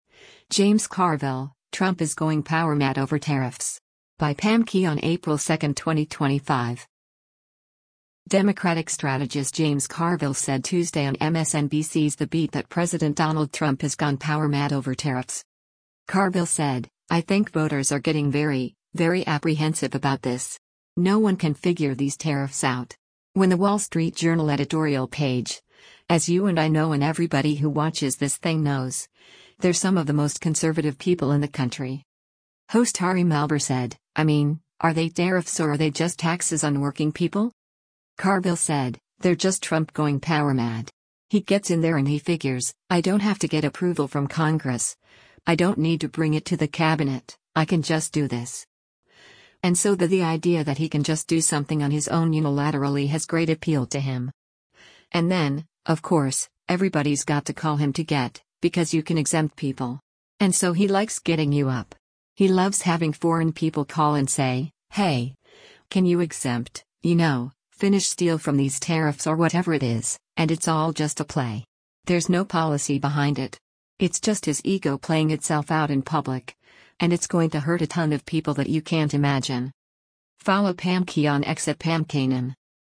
Democratic strategist James Carville said Tuesday on MSNBC’s “The Beat” that President Donald Trump has gone “power mad” over tariffs.